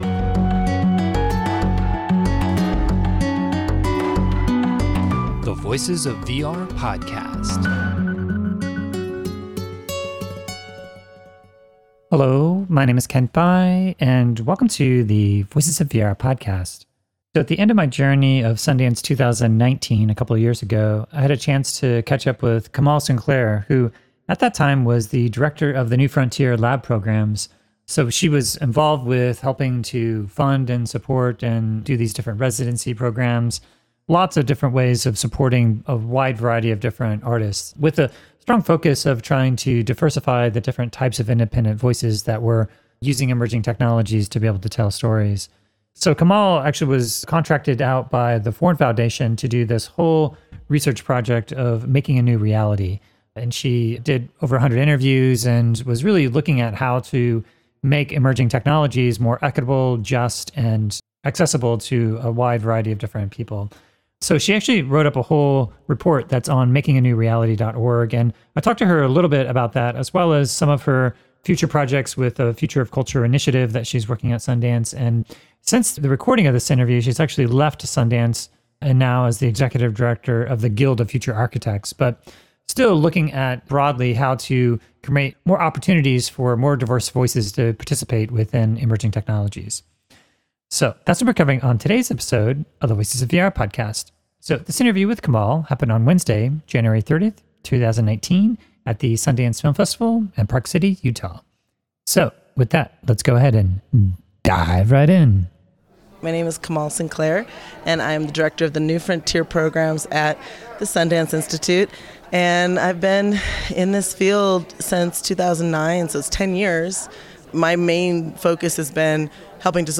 At Sundance 2019